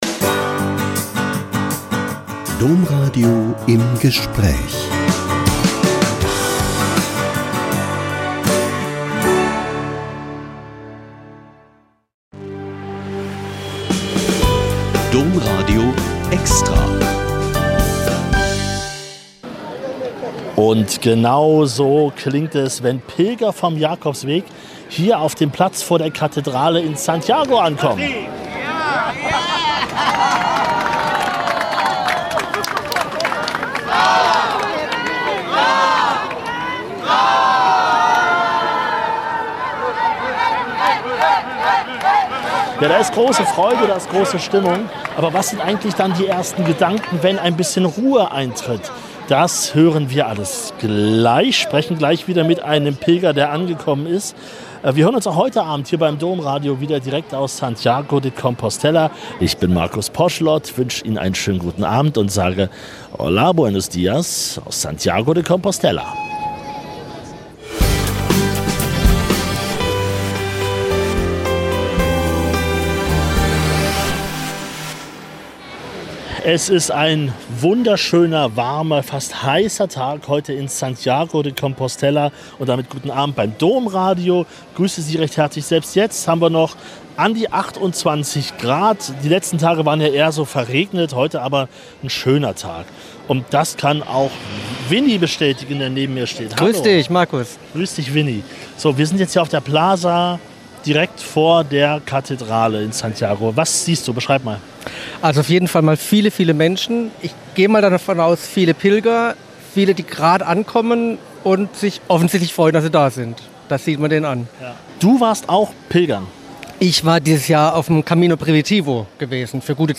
Heute unter anderem mit einem Pilgerforscher, der sich mit Pilger-Tattoos beschäftigt hat und der Deutschsprachigen Pilgerseelsorge.